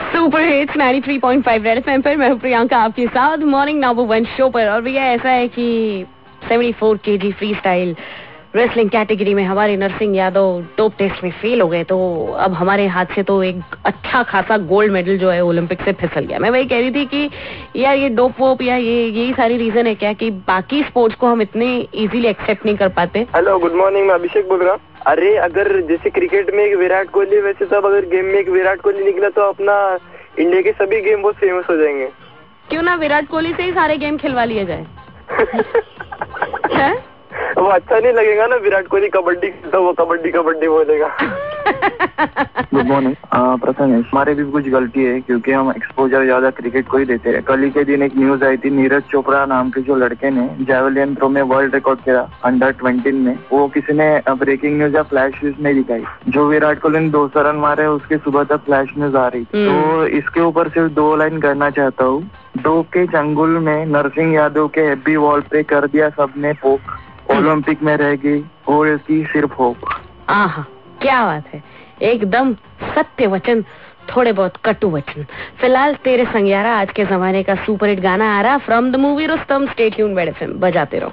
26JUL_L12_CALLER INTERACTION